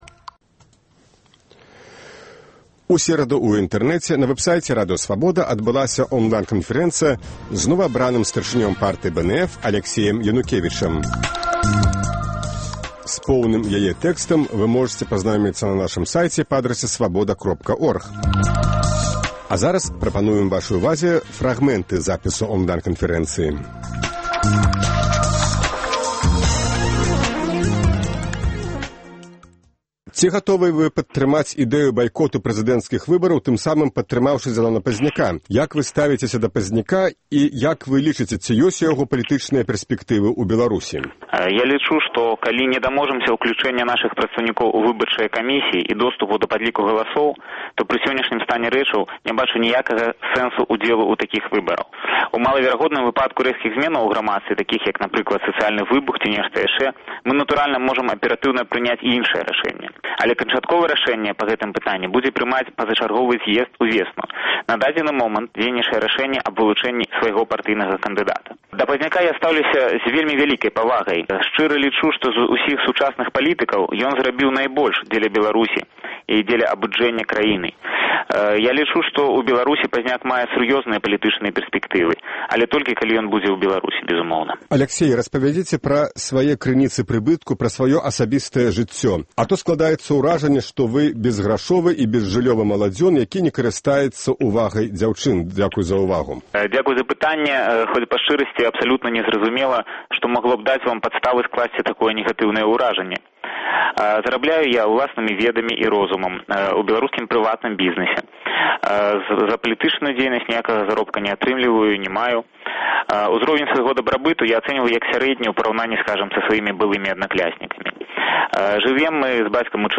Онлайн-канфэрэнцыя